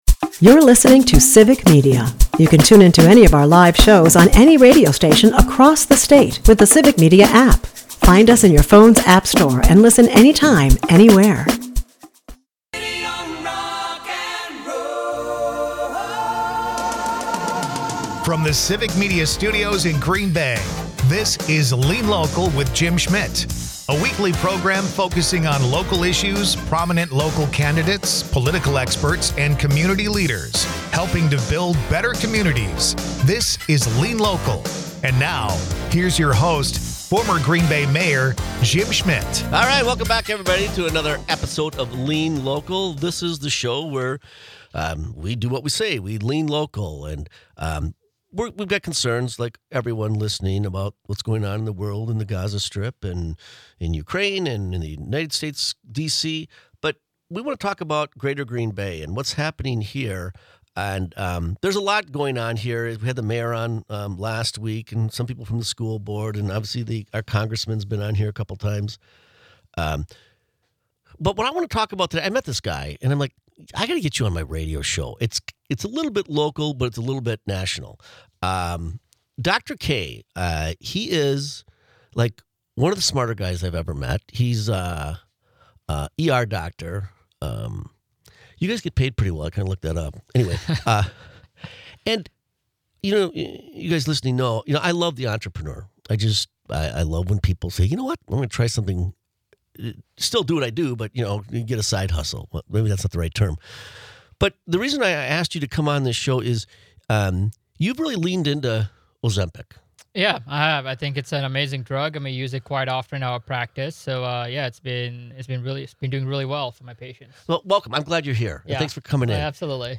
Lean Local is a part of the Civic Media radio network and airs Sunday's from 1-2 PM on WGBW .
Dive into the heart of community issues with 'Lean Local,' hosted by former Green Bay Mayor Jim Schmitt.